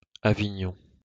aviňon), je starobylé město v Provence na jihu Francie, sídlo departementu Vaucluse v regionu Provence-Alpes-Côte d'Azur.